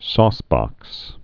(sôsbŏks)